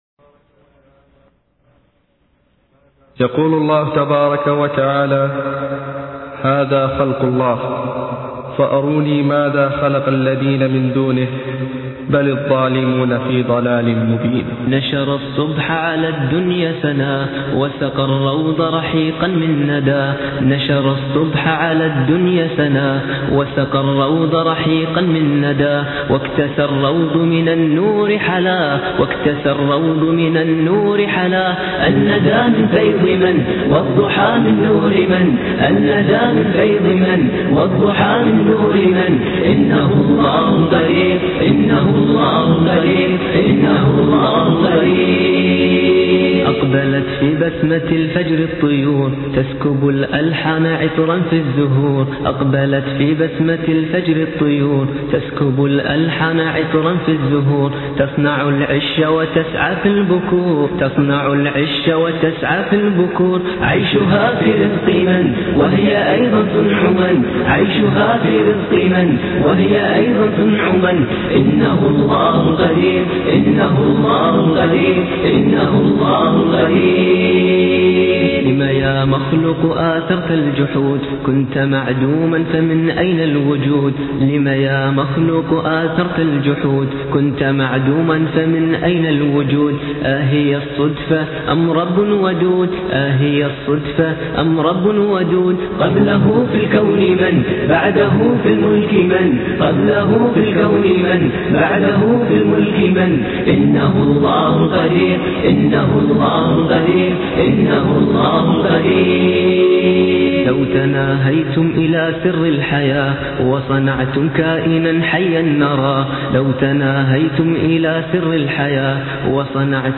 نشيدة قديمة .. تعجبني دائماً ..
العجيب أن النشيده قديمه جداً ويلاحظ فيها الهارمونيات متناغمه بشكل رهيييييب
شف يابعدي ، لو يعيدون تسجيله كان تطلع شينه ، هذي ميزته وأنا أخوك إن تسجيله قديم ، ومابه مطرسه من مطاريس التالين .